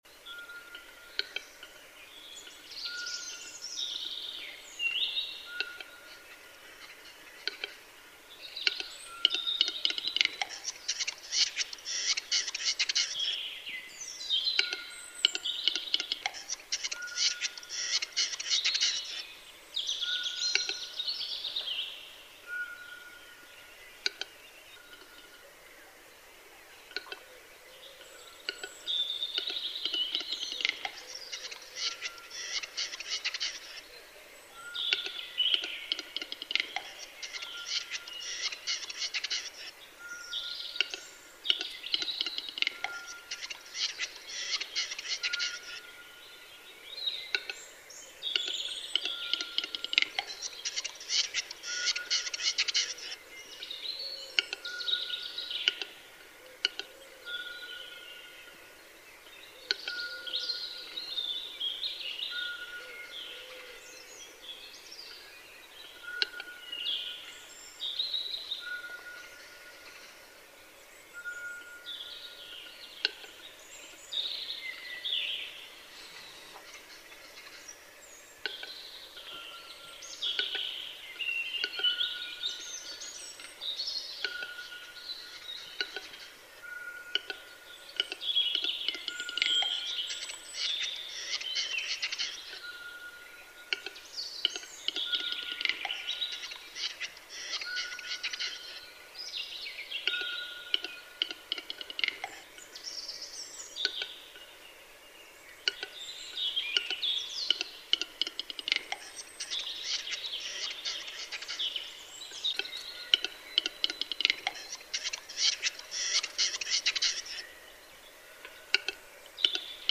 XC26782-Western-Capercaillie-Tetrao-urogallus.mp3